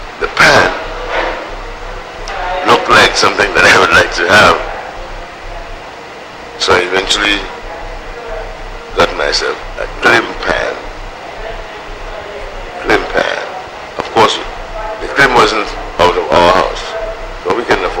1 audio cassette
The Oral and Pictorial Records Programme (OPReP)